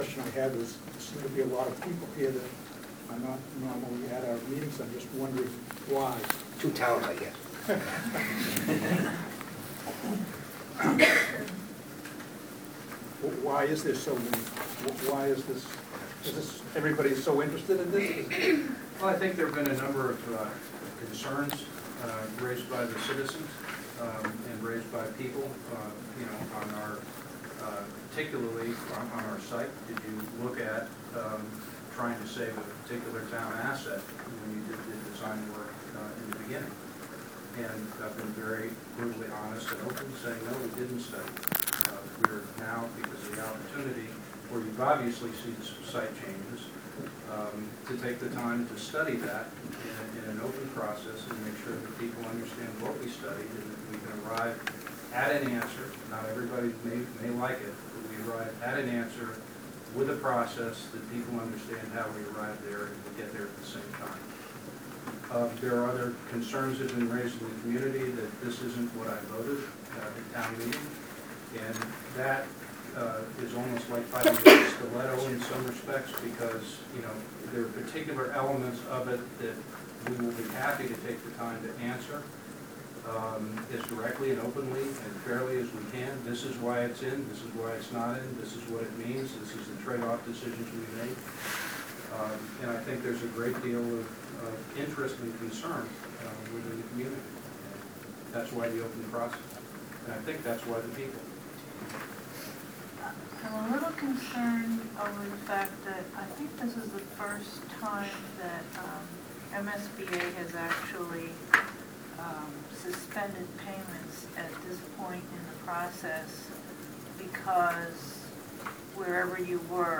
MSBA, Facilities Assessment Subcommittee (FAS), meeting August 29 2012 – audio with text, final 7+ minutes